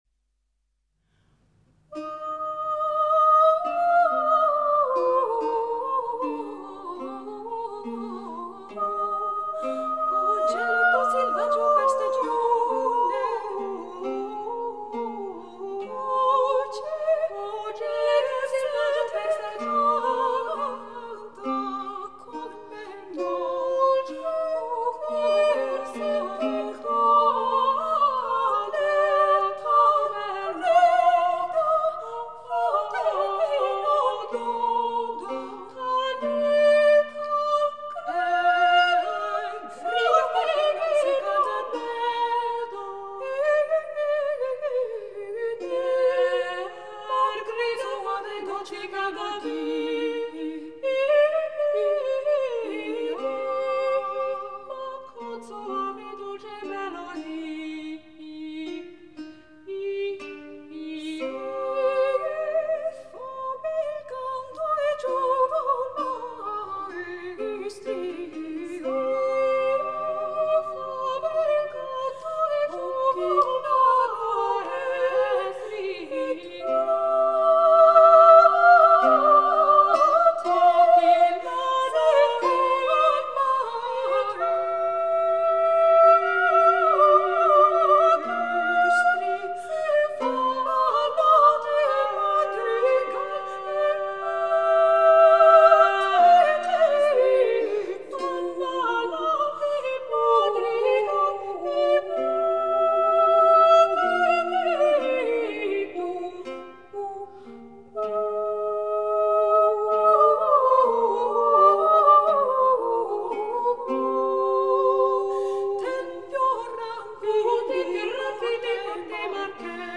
Oselletto selvagio - Caccia — Laurea Magistrale in Culture e Tradizioni del Medioevo e del Rinascimento